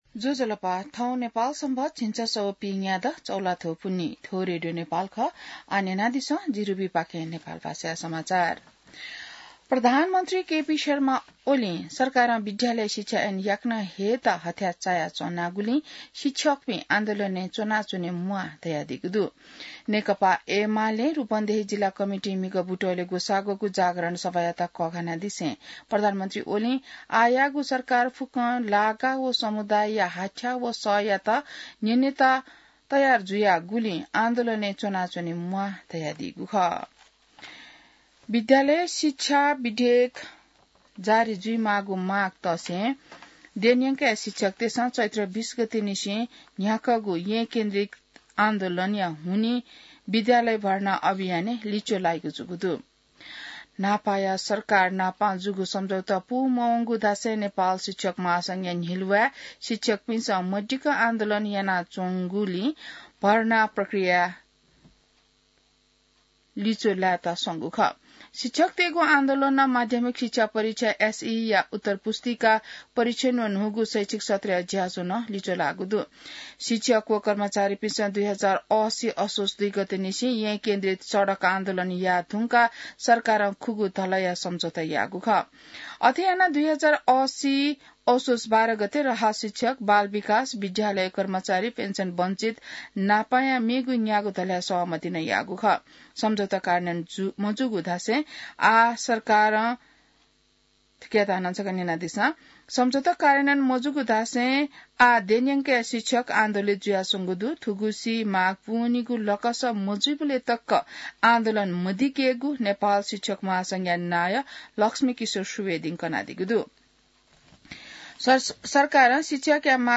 नेपाल भाषामा समाचार : ३० चैत , २०८१